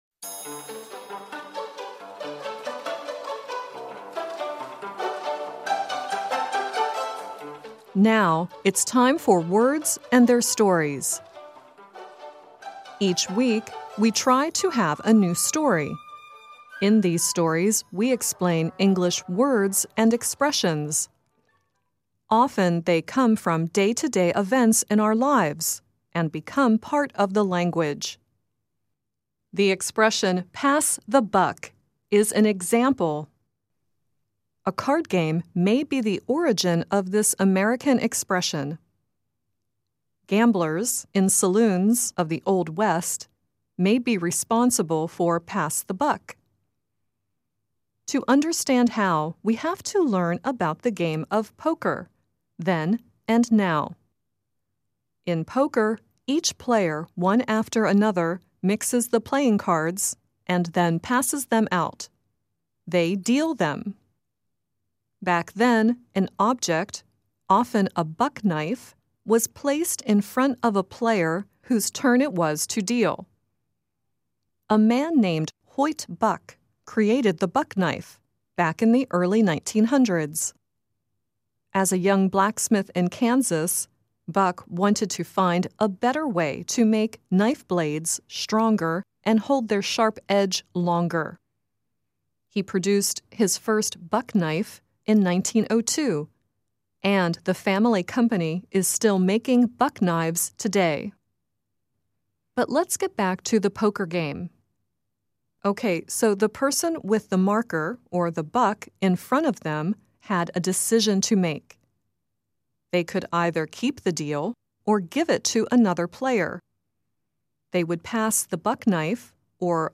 The song at the end is Kenny Rogers singing "The Gambler."